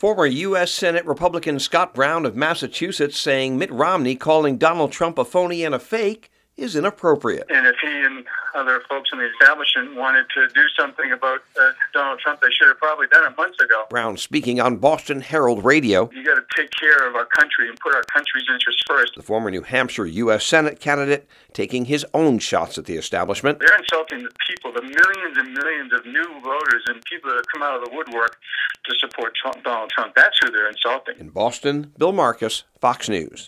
(BOSTON) MARCH 3 – FORMER MASSACHUSETTS SENATOR SCOTT BROWN – A TRUMP SUPPORTER – COMING OUT SWINGING FOR HIS CANDIDATE ON HERALD RADIO IN BOSTON.